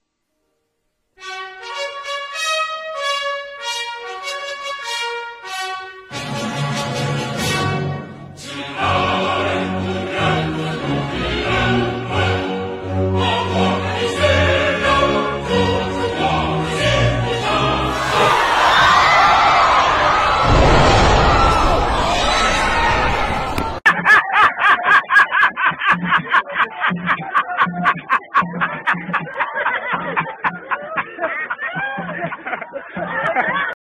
Chinese chorus national anthem